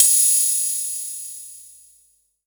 prcTTE47030techno.wav